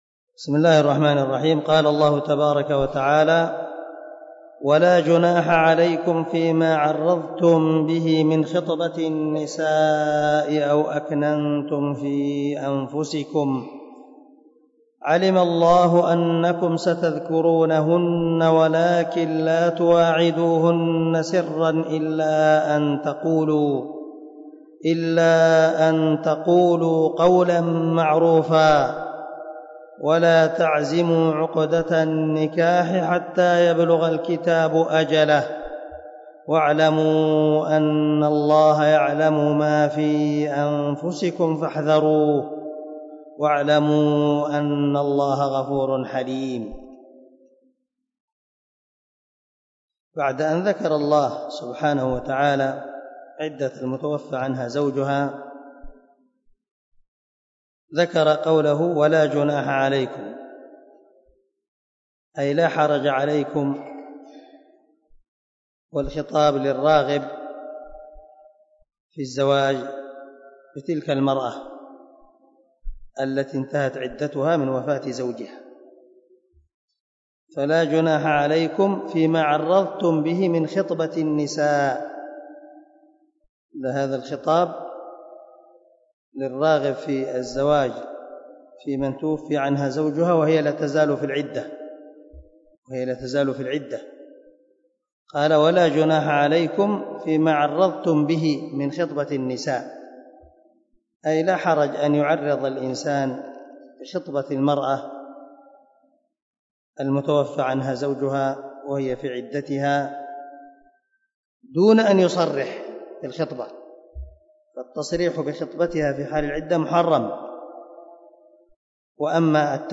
123الدرس 113 تفسير آية ( 235 ) من سورة البقرة من تفسير القران الكريم مع قراءة لتفسير السعدي
دار الحديث- المَحاوِلة- الصبيحة.